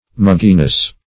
Search Result for " mugginess" : Wordnet 3.0 NOUN (1) 1. a state of warm humidity ; The Collaborative International Dictionary of English v.0.48: Mugginess \Mug"gi*ness\, n. The condition or quality of being muggy.
mugginess.mp3